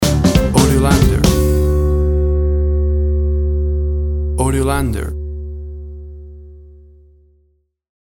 WAV Sample Rate 16-Bit Stereo, 44.1 kHz
Tempo (BPM) 90